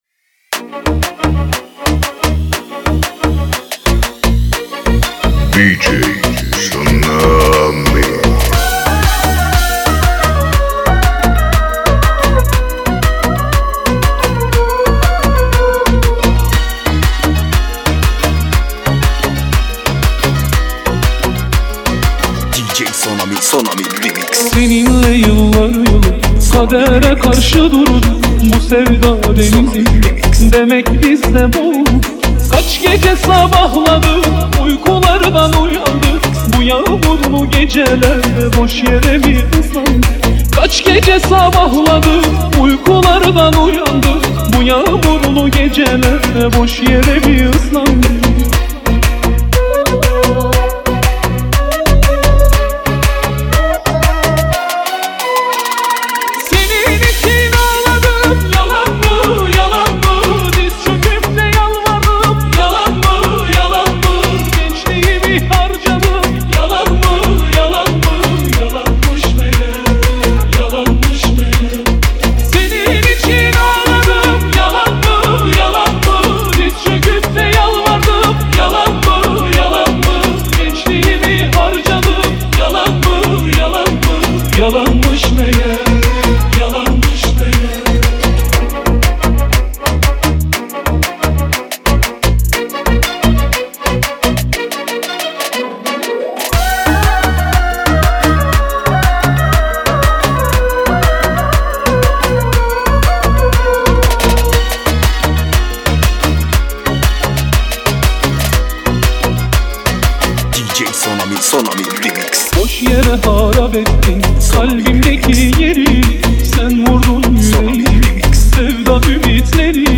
ریمیکش شاد